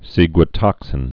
(sēgwə-tŏksĭn)